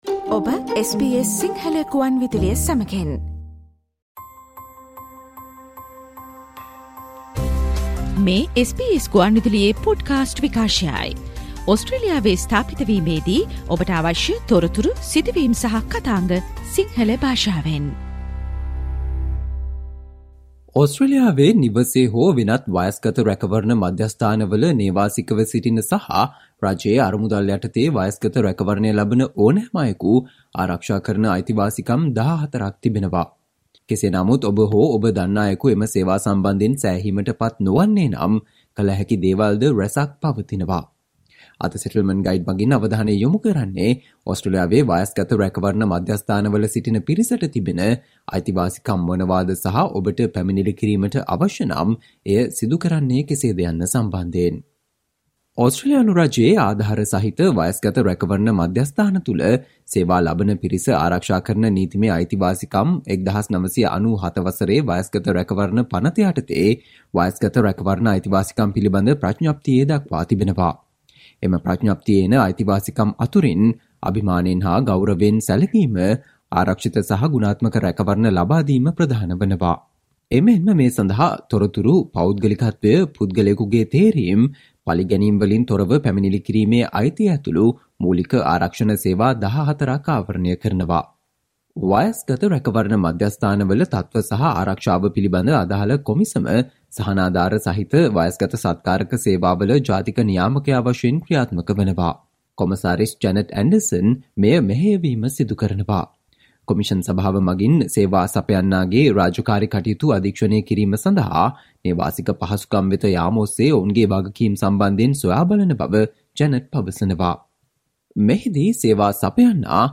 ඔස්ට්‍රේලියාවේ නවතම පුවත්, ශ්‍රී ලංකාවේ පුවත් , විදෙස් පුවත් සහ ක්‍රීඩා පුවත් රැගත් SBS සිංහල සේවයේ 2023 පෙබරවාරි 07 වෙනි අඟහරුවාදා ප්‍රවෘත්ති ප්‍රකාශයට සවන් දෙන්න.